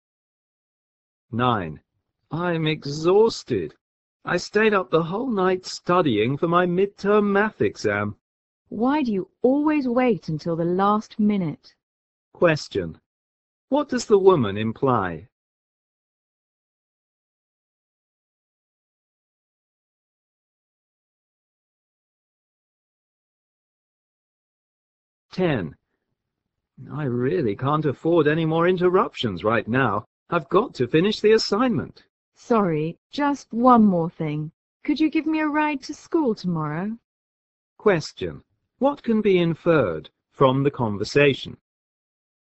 在线英语听力室093的听力文件下载,英语四级听力-短对话-在线英语听力室